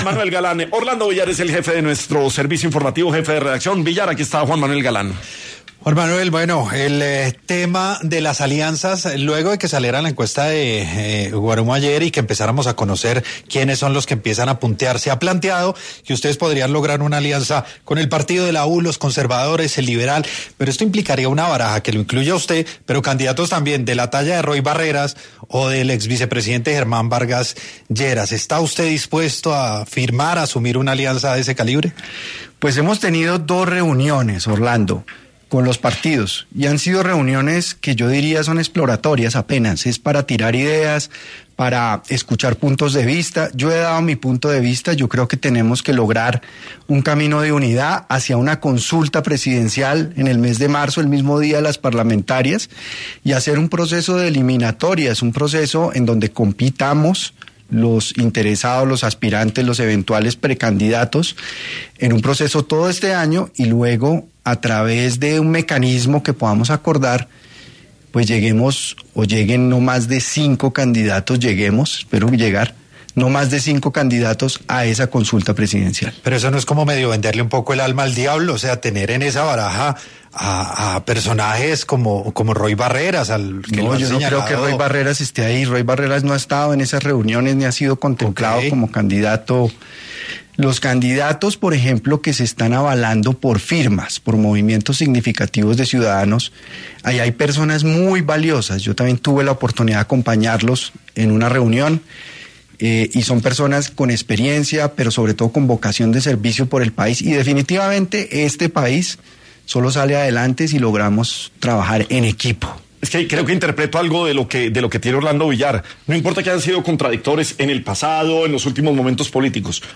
En entrevista para “Sin Anestesia”, el precandidato Juan Manuel Galán, quien ha expresado la voluntad de concretar coaliciones para competir en las elecciones del 2026, habló sobre sus planes y el desarrollo que tienen hasta el momento.